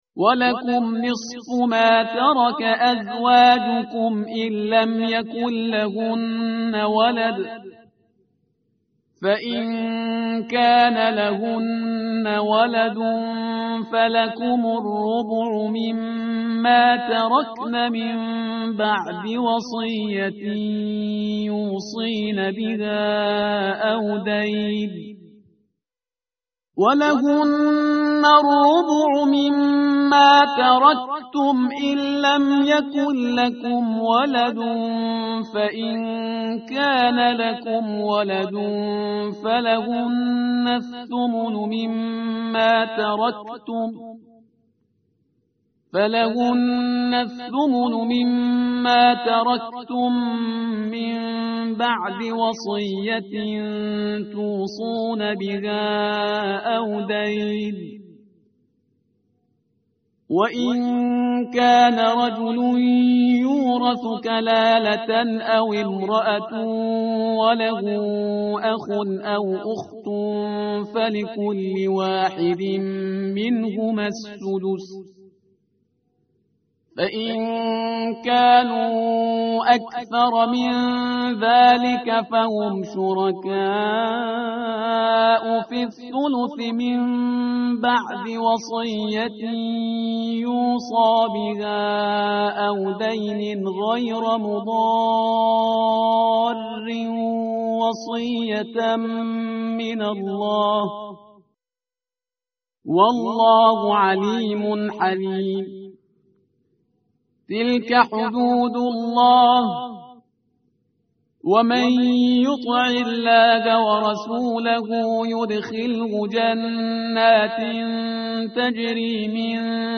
متن قرآن همراه باتلاوت قرآن و ترجمه
tartil_parhizgar_page_079.mp3